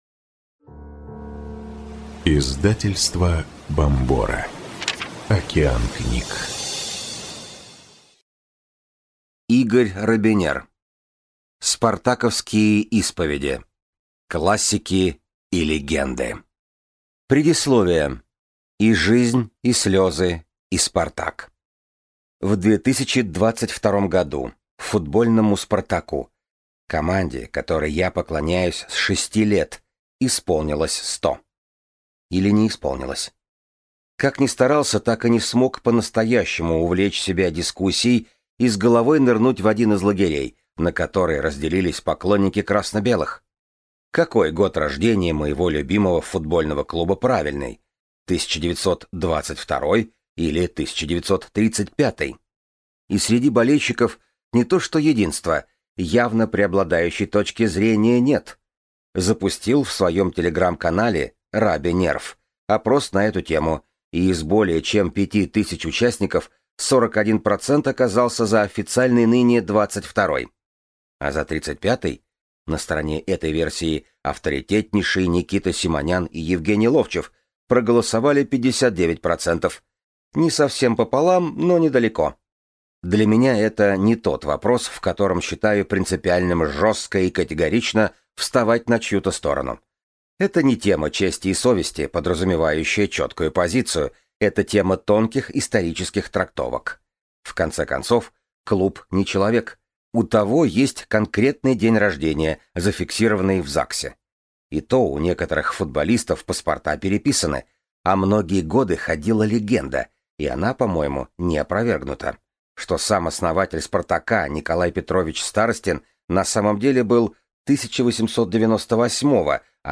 Аудиокнига Спартаковские исповеди. Классики и легенды | Библиотека аудиокниг